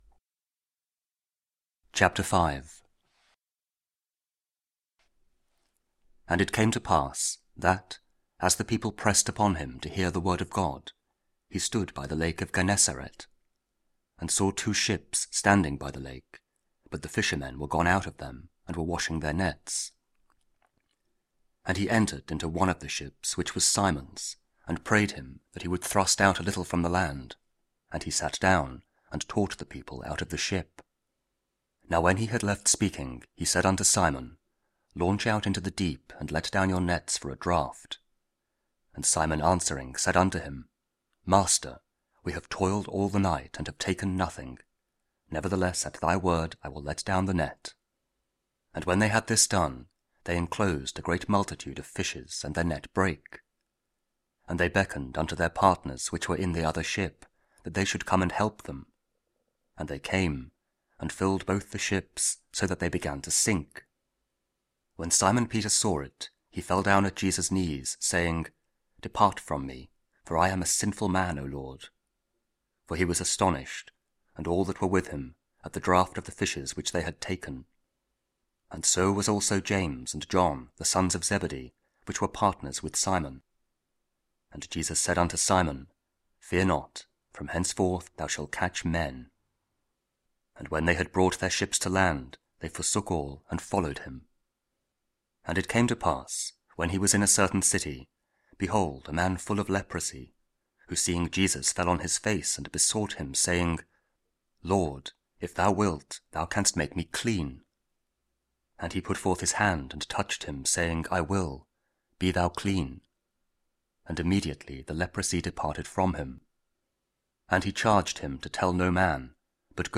Luke 5: 12-16 – Friday after Epiphany – also 11 January (KJV Audio Bible, Spoken Word)